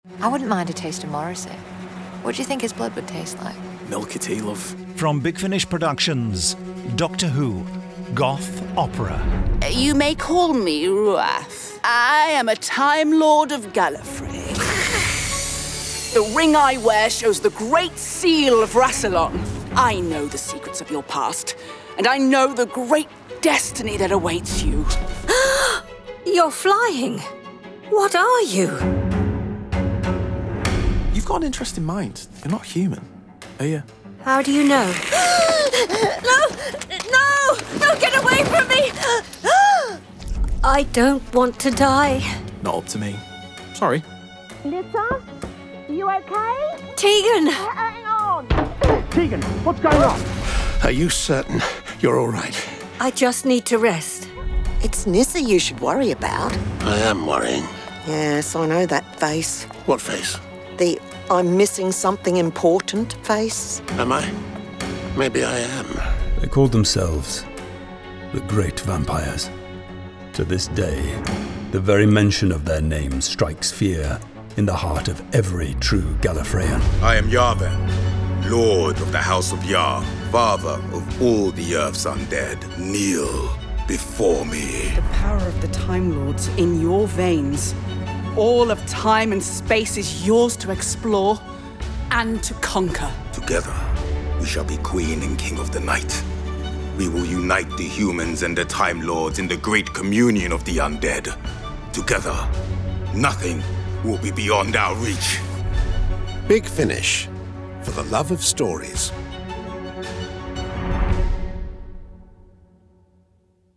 Award-winning, full-cast original audio dramas from the worlds of Doctor Who
12. Doctor Who: Goth Opera Released July 2024 Written by Paul Cornell Adapted by Lizbeth Myles Starring Peter Davison Janet Fielding This release contains adult material and may not be suitable for younger listeners. From US $27.77 CD + Download US $34.08 Buy Download US $27.77 Buy Login to wishlist 39 Listeners recommend this Share Tweet Listen to the trailer Download the trailer